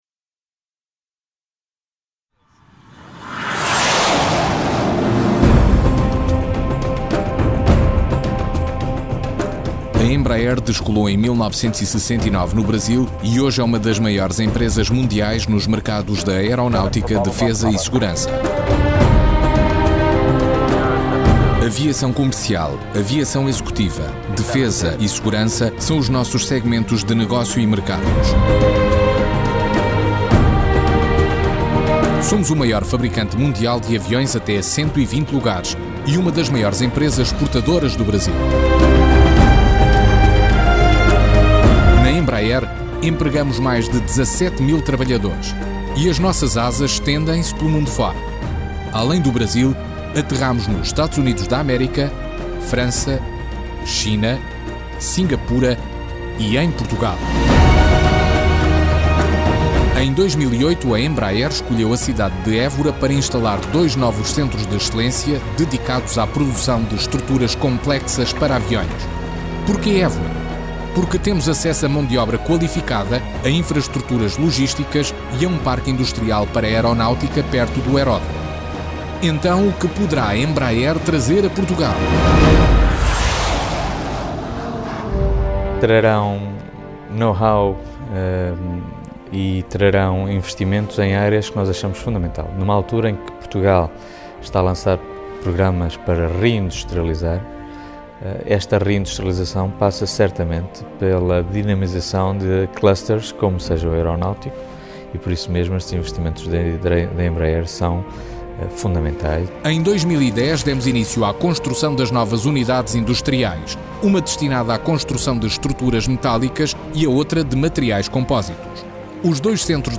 Portuguese Voice Over.
Sprechprobe: Industrie (Muttersprache):